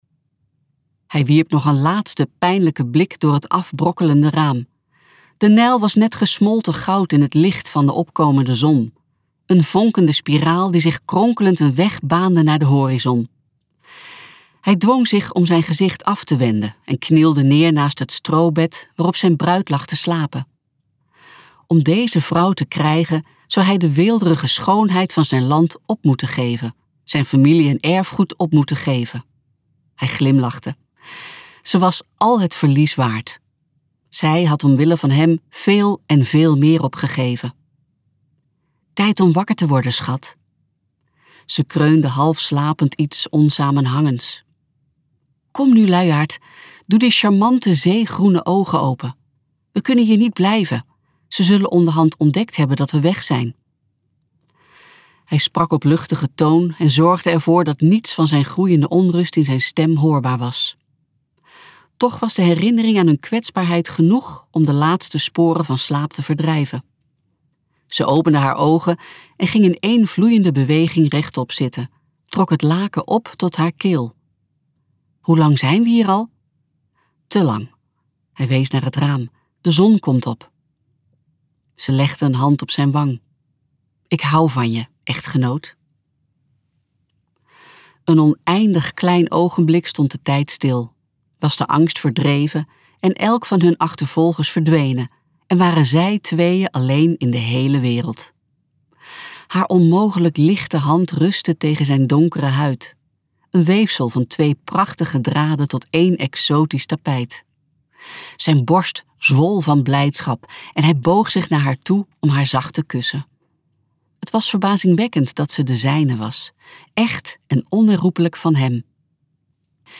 KokBoekencentrum | Juweel van de nijl luisterboek